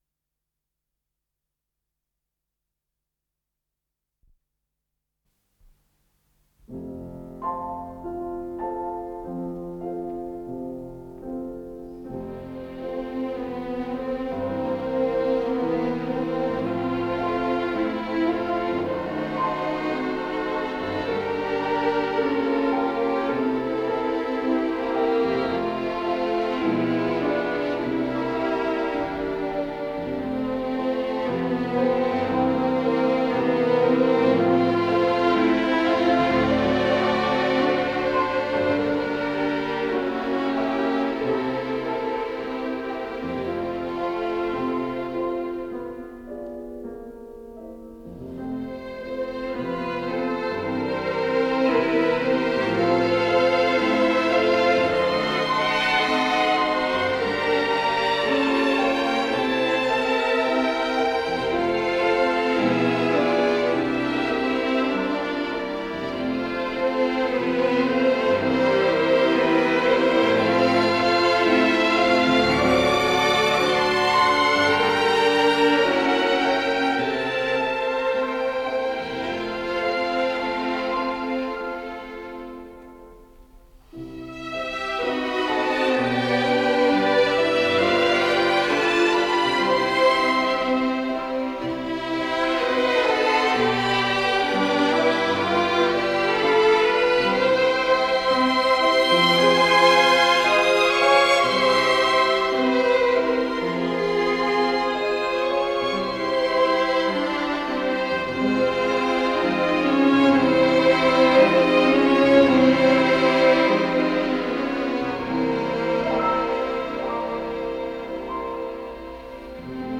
с профессиональной магнитной ленты
ИсполнителиАнсамбль скрипачей Государственного Академического Большого театра СССР
ВариантДубль стерео